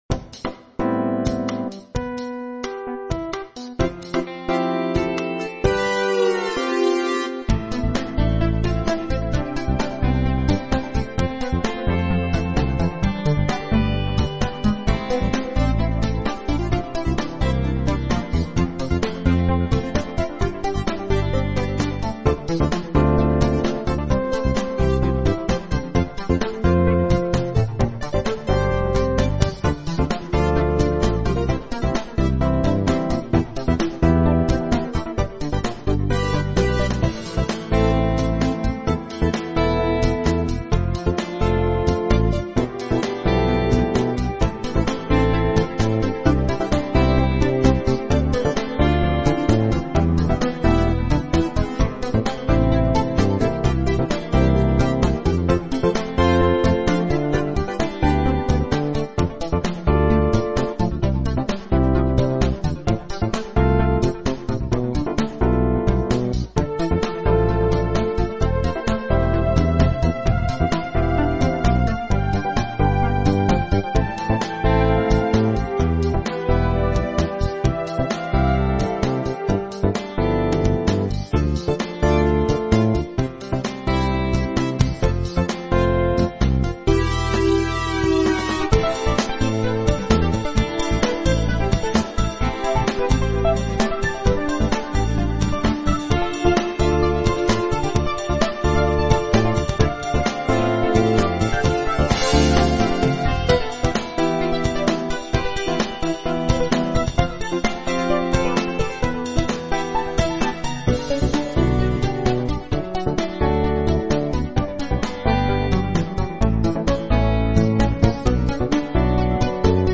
Rhythmic Soundtrack music in style of 1980's Funk-Pop